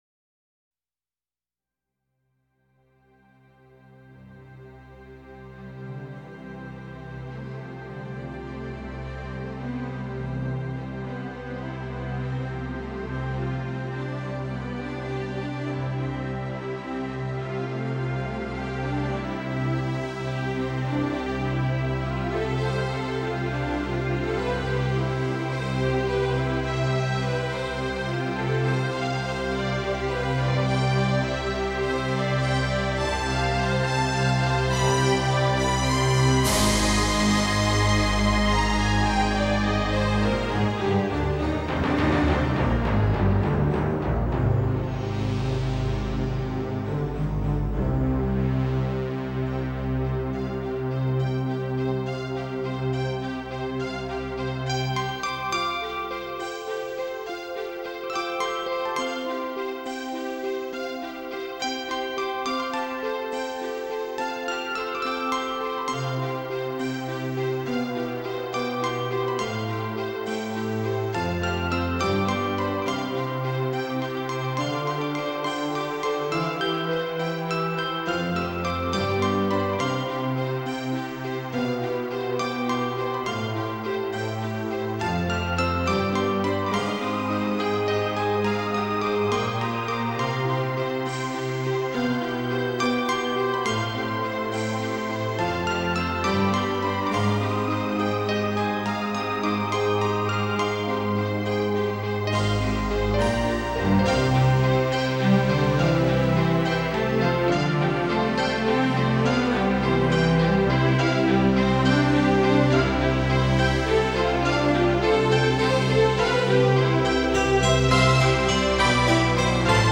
Нью эйдж New age